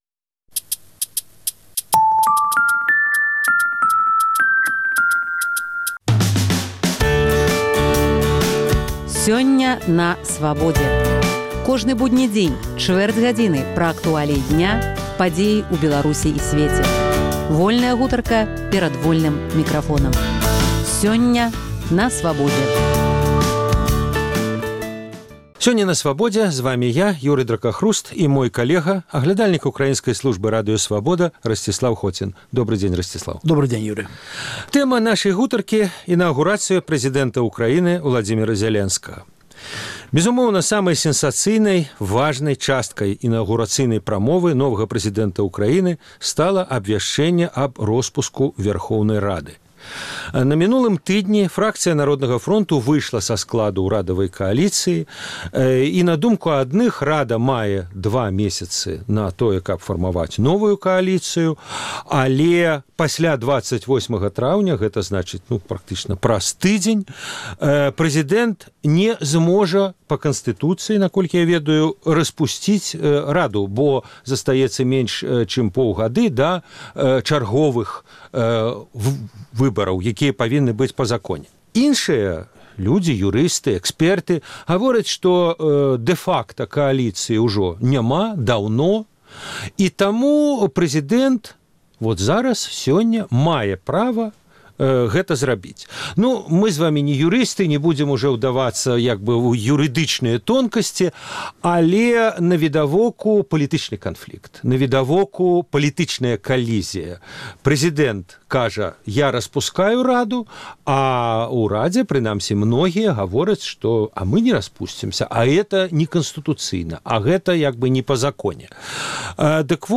якія зрабіў сваёй інаўгурацыйнай прамове новы прэзыдэнт Украіны Цыкль "Невядомы Данчык" - гутаркі зь легендарным беларускім сьпеваком з Амэрыкі, нашчадкам сям'і Луцкевічаў Багданам Андрусышыным.